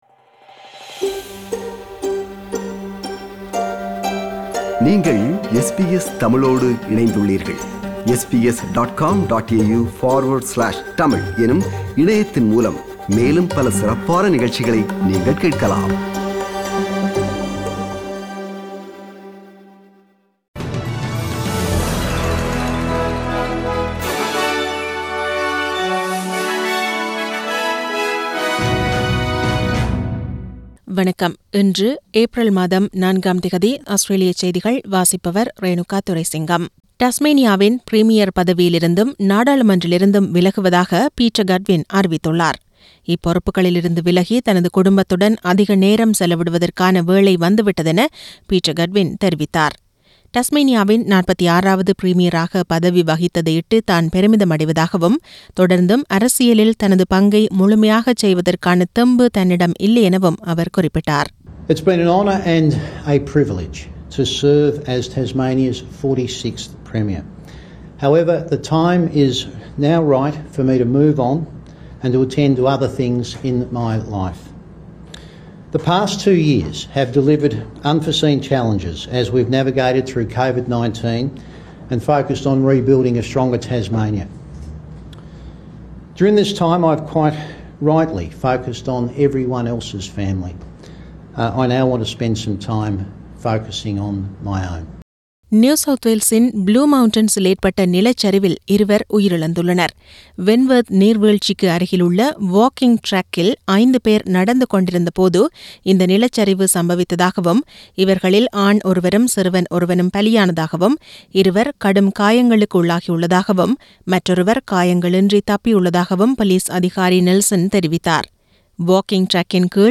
Australian news bulletin